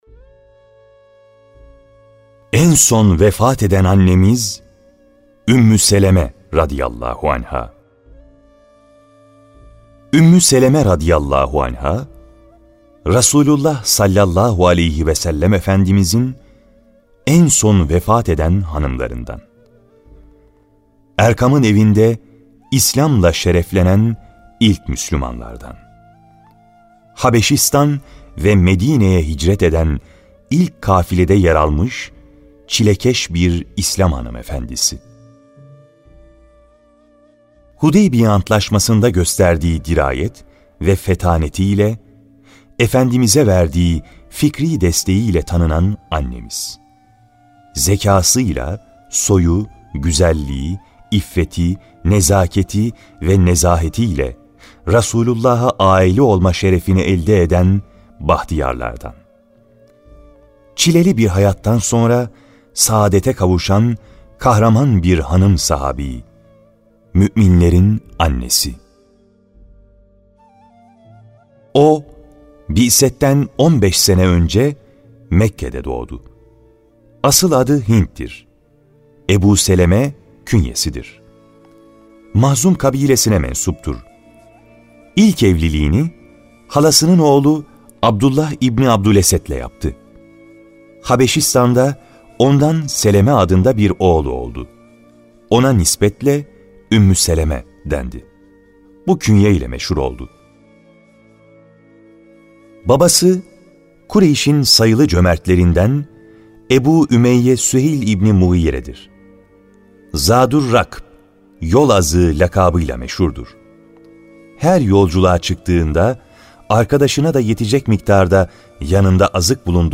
HANIM SAHABÎLER (Sesli Kitap)